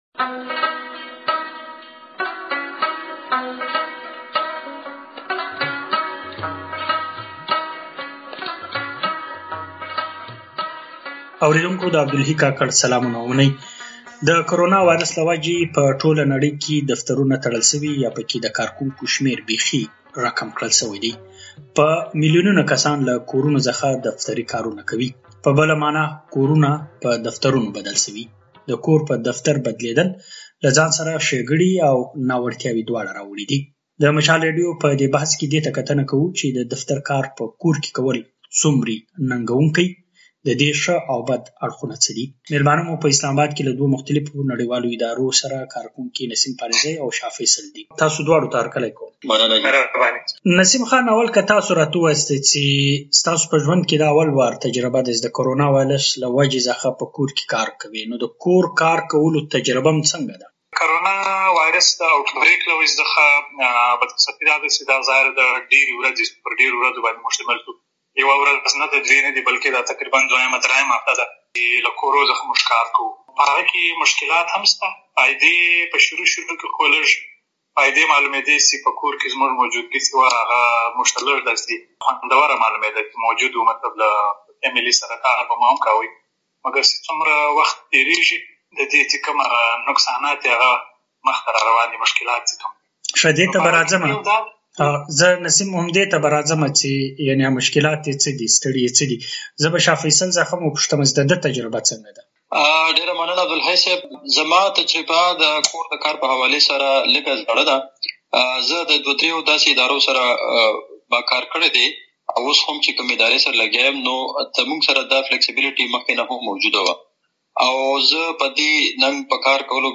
د مشال راډيو په دې بحث کې جاج اخيستل شوی چې د دفتر کار پر کور کولو ګټې او تاوانونه څه دي او پر کور د دفتر کار کولو پر مهال کومې خبرې په پام کې نيول پکار دي.؟